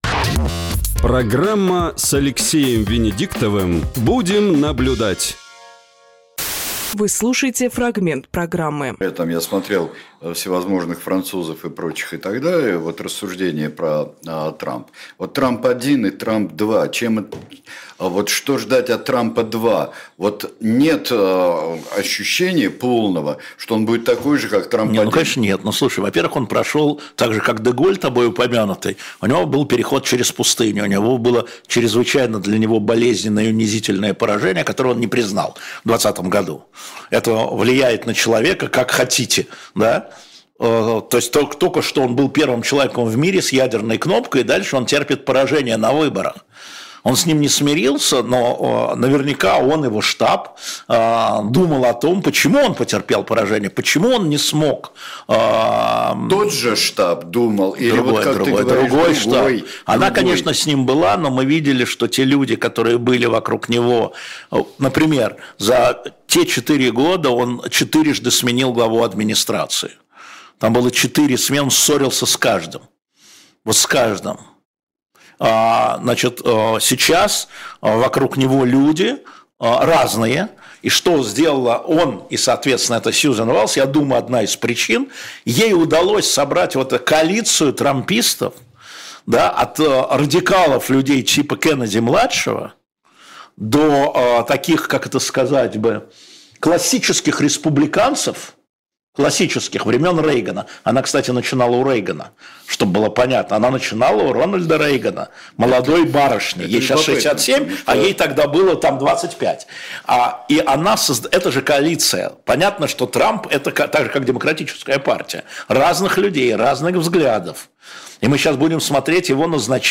Фрагмент эфира от 09.11.24